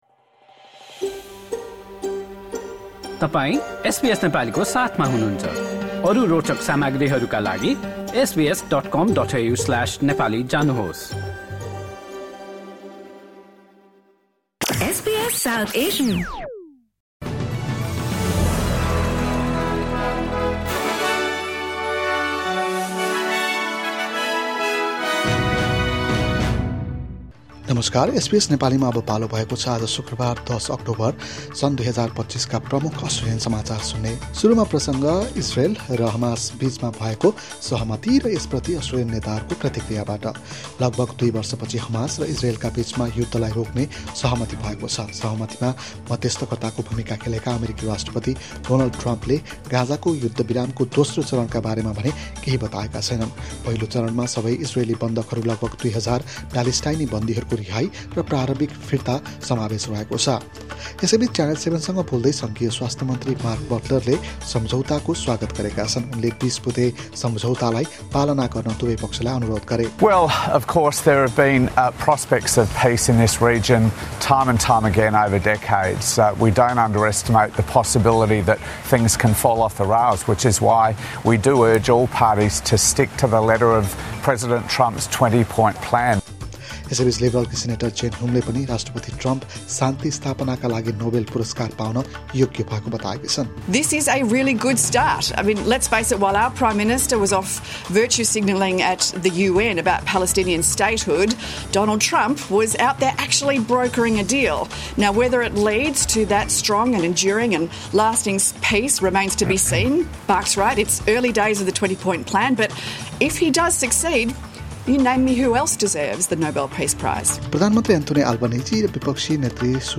आजका प्रमुख अस्ट्रेलियन समाचार छोटकरीमा सुन्नुहोस्।